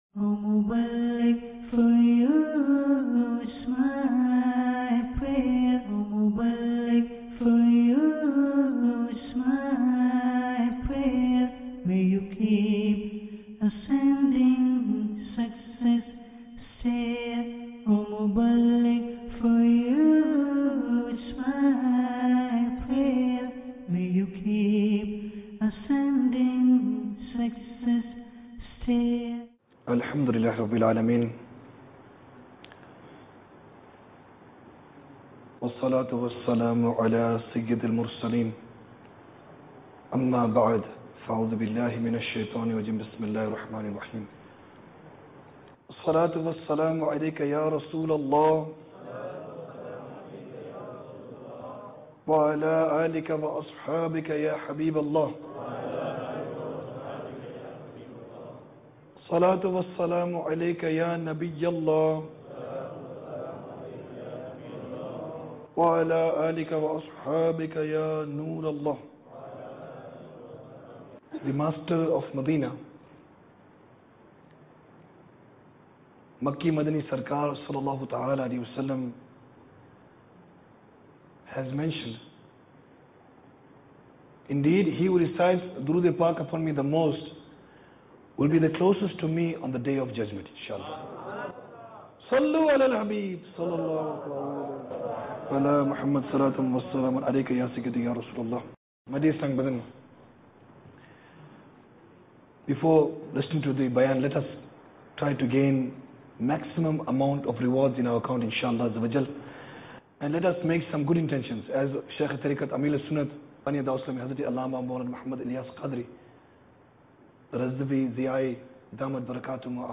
Sunnah Inspired Bayan Ep 247 - Showing Off Mar 10, 2017 MP3 MP4 MP3 Share A Sunnah Inspired Bayan emphasizing the side effects of showing-off, which is a bad sin and at the Day of Judgment those who do so will be entitled with wicked names.